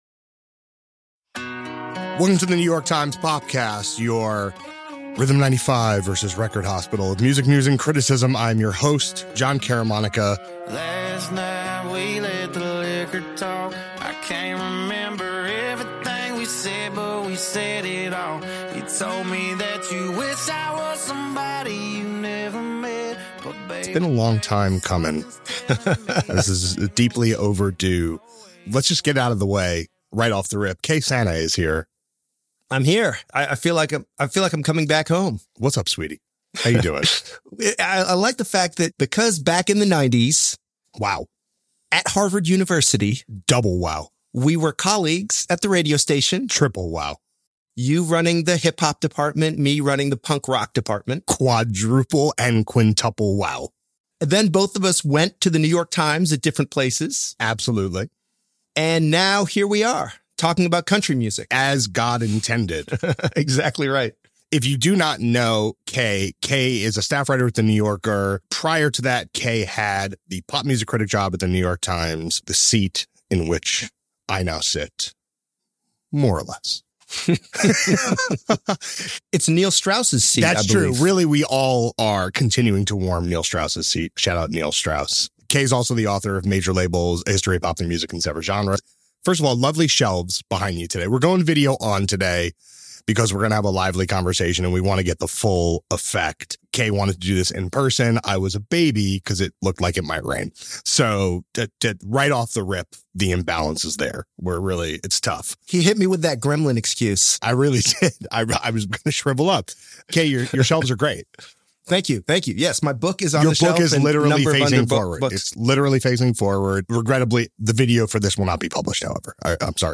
As the country star’s new album earns the biggest debut of the year, a conversation about the nature of his fame and the scope of his reprimand for using a racial slur. Guest: The New Yorker's Kelefa Sanneh.